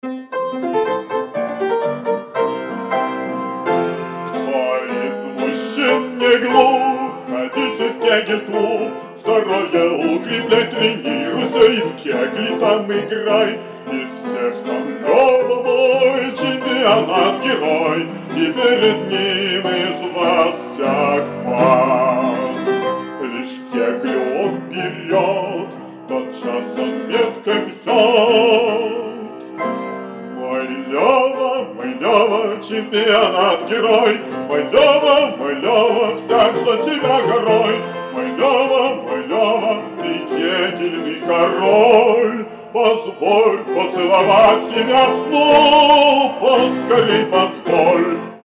фортепиано